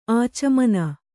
♪ ācamana